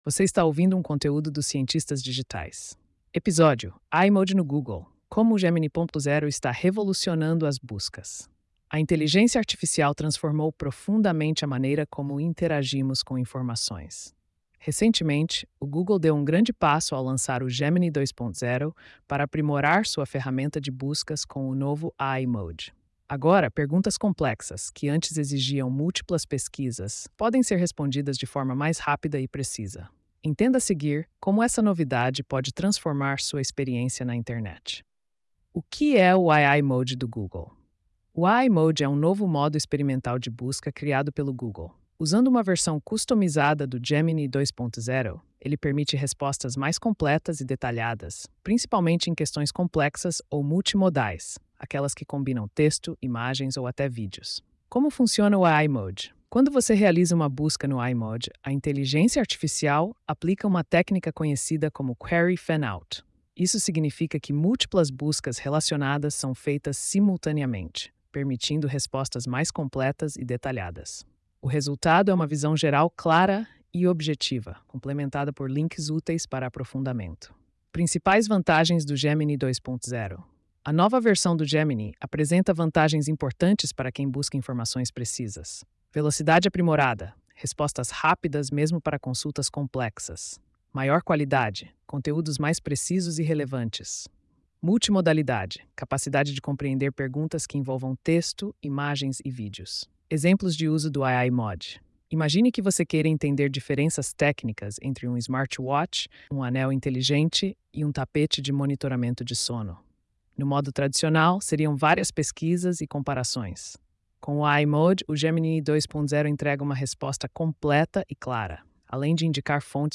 post-2816-tts.mp3